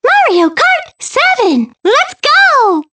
One of Princess Peach's voice clips in Mario Kart 7